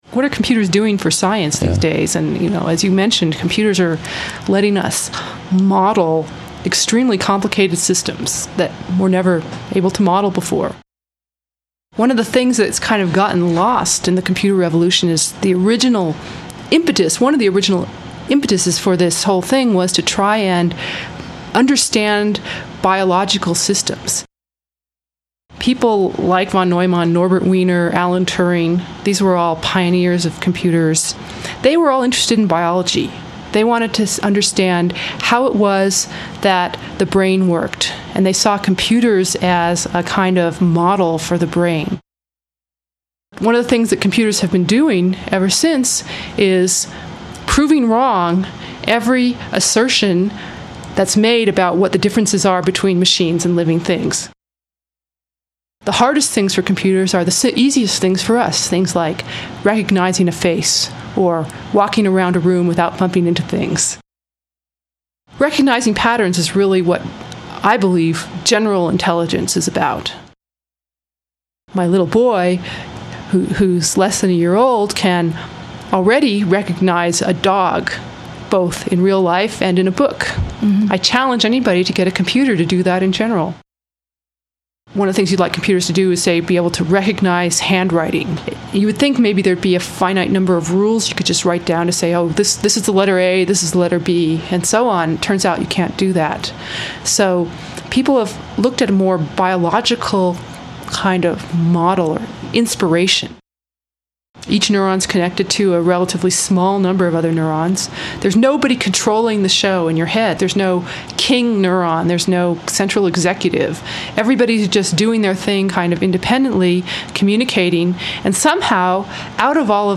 [This Program was recorded January 24, 2000, in Los Alamos, New Mexico, US.]